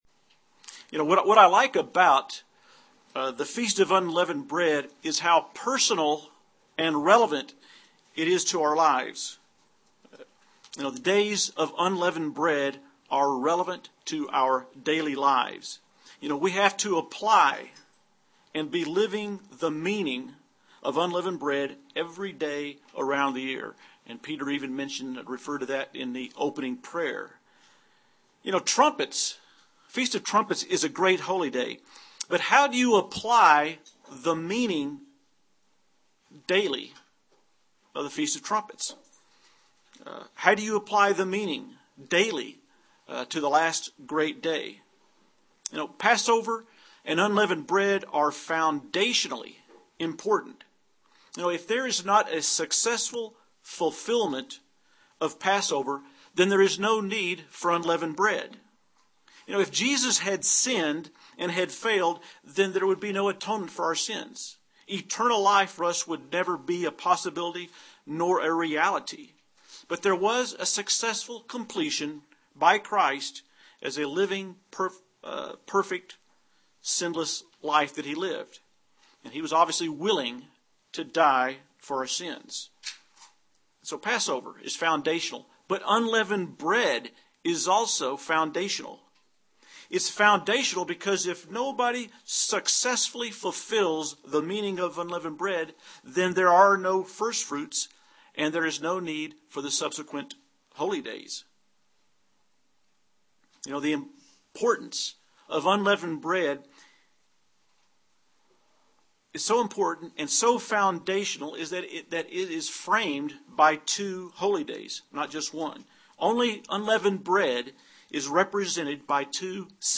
Given in Albuquerque, NM
UCG Sermon Studying the bible?